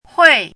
拼音： huì
注音： ㄏㄨㄟˋ
hui4.mp3